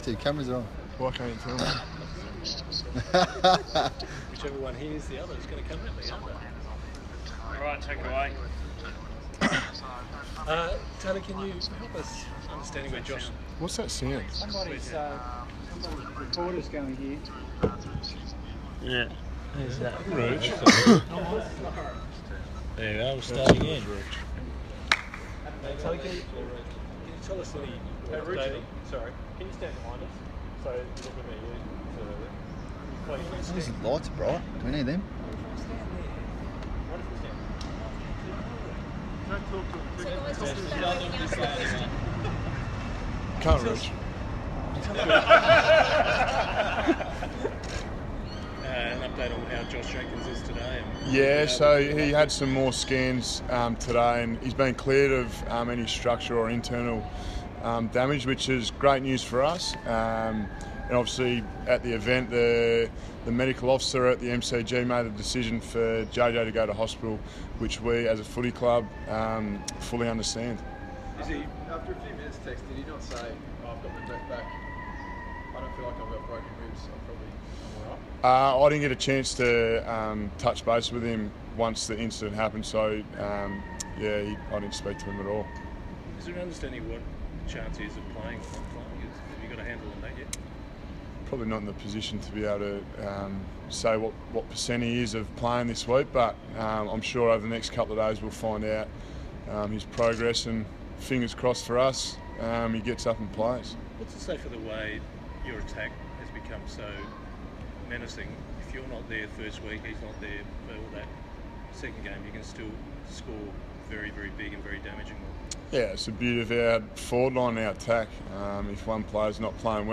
Showdown Captains' Press Conference
Travis Boak and Taylor Walker talk with media ahead of Saturday night's Showdown XLII.